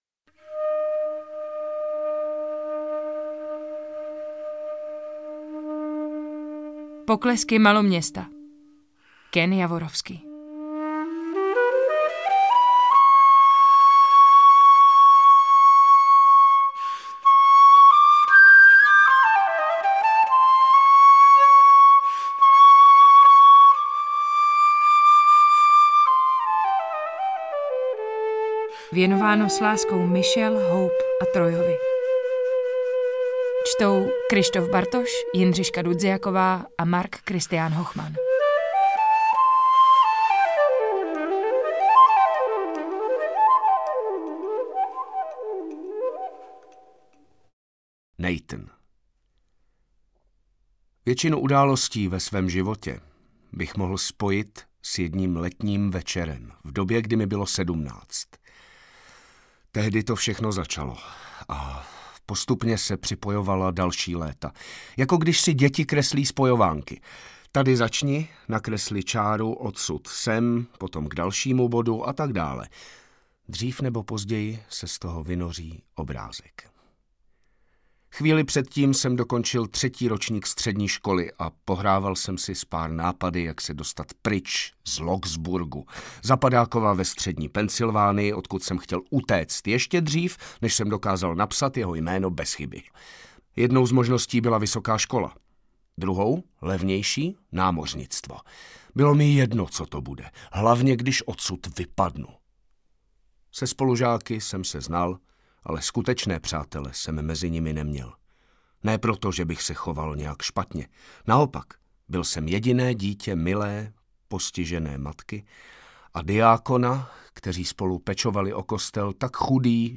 AudioKniha ke stažení, 32 x mp3, délka 7 hod. 59 min., velikost 449,9 MB, česky